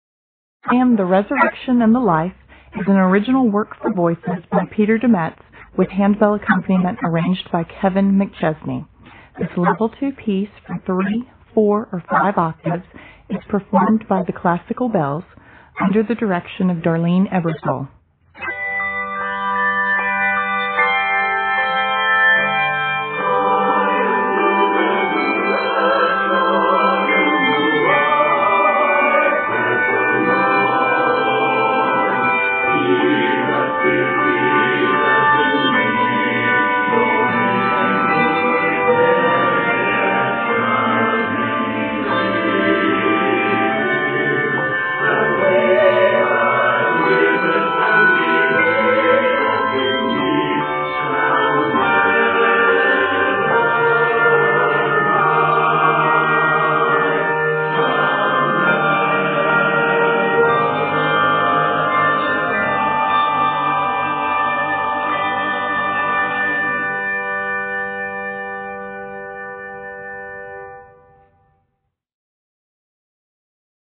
An SATB choir or ensemble joins 3-5 octaves of bells